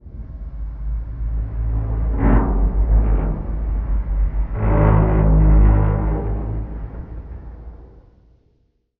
metal_low_creaking_ship_structure_08.wav